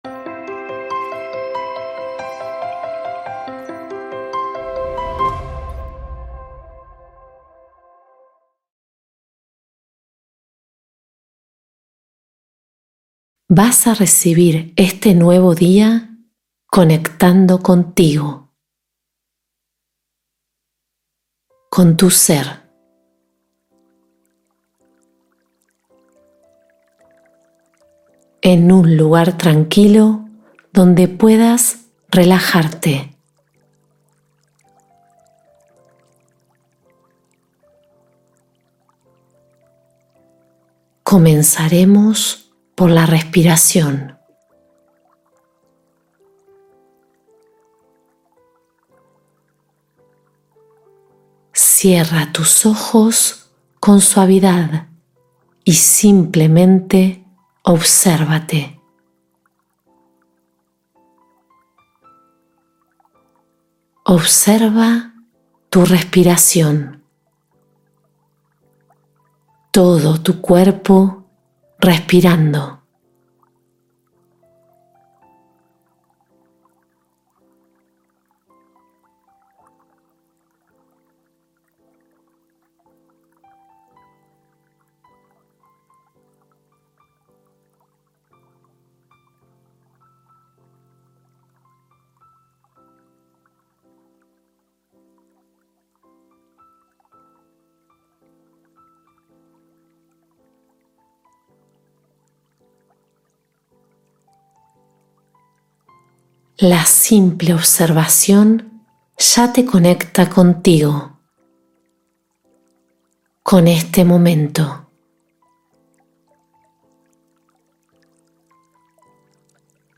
Comienza el día con una actitud positiva con esta meditación guiada matutina